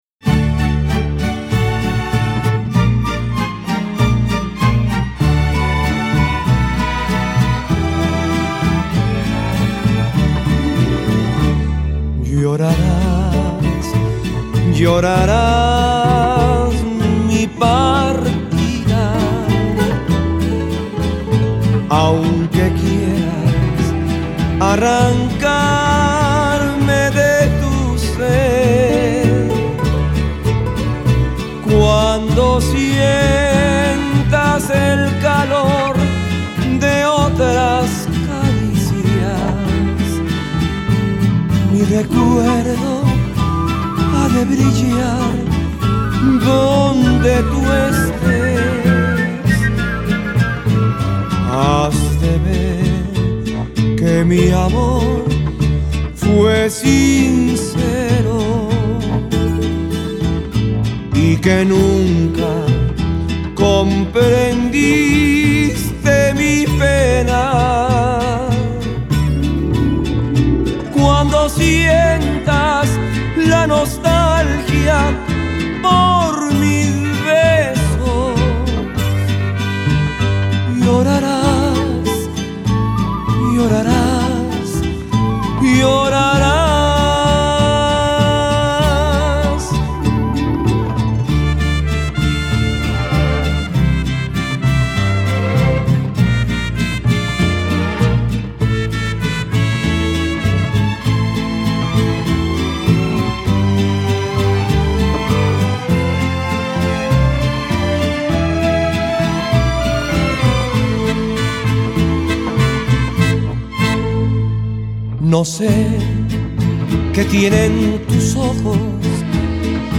versión estudio-campirana
la versión de estudio